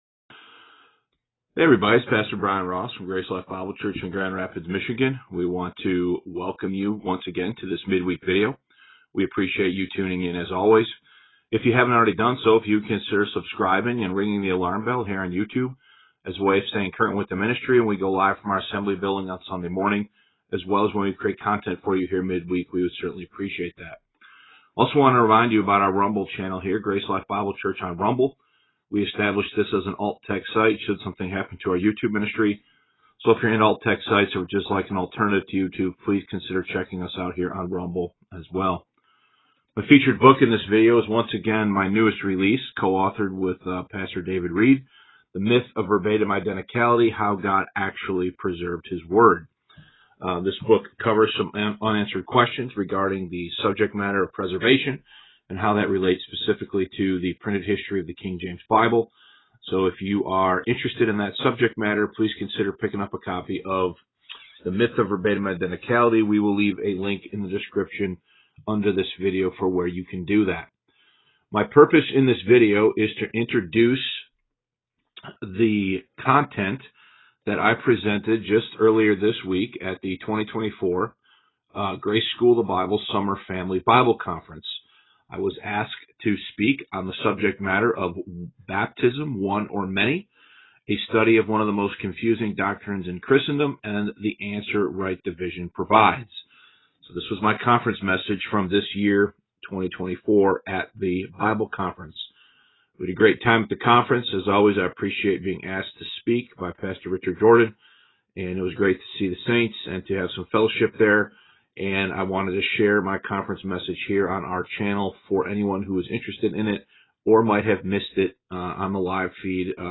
Baptism: One Or Many? (2024 GSB Summer Family Conference Message)